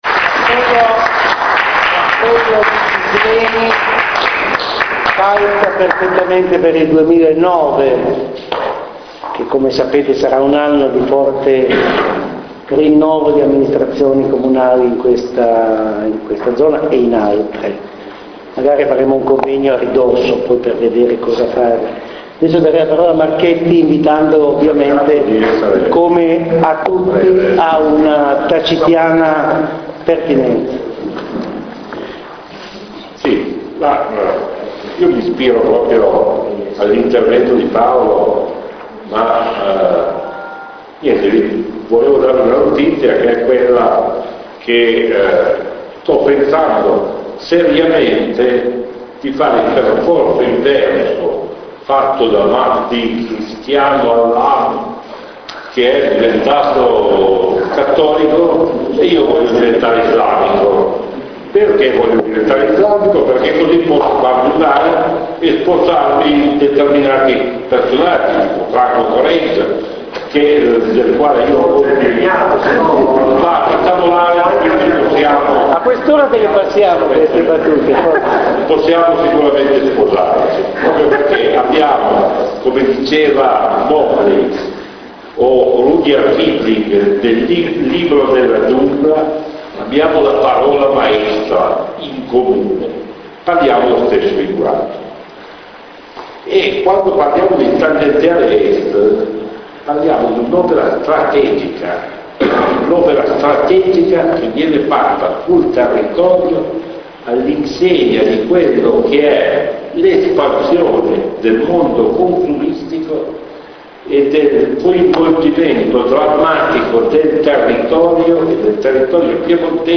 Resoconto Stati generali del Paesaggio astigiano a Moncucco Torinese (21 giugno 2008)
Relazione